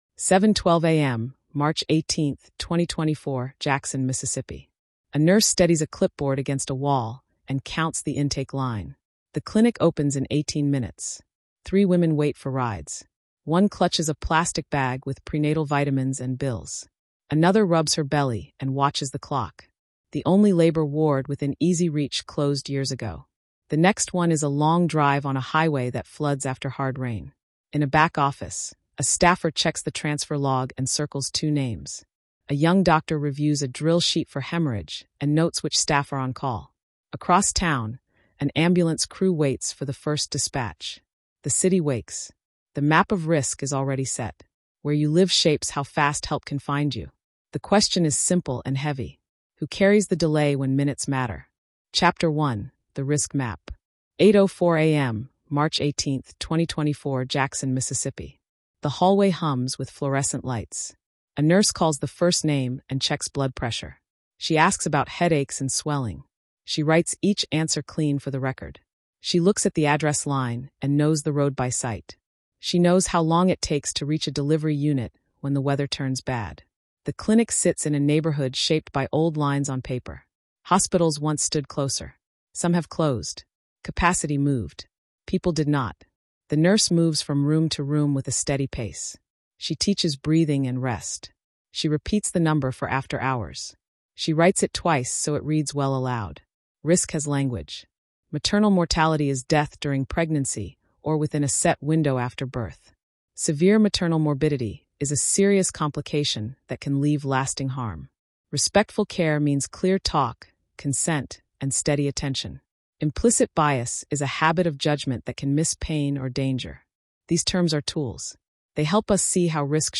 A hard-hitting investigative documentary in a 20/20 cadence. We map how distance, staffing, and bedside gaps shape maternal risk — and why outcomes split by zip code and race. Field reporting, verified timelines, and review-room accountability reveal what shrinks the deadly stretch between warning and help. Urgent, dignified, and clear.